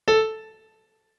MIDI-Synthesizer/Project/Piano/49.ogg at 51c16a17ac42a0203ee77c8c68e83996ce3f6132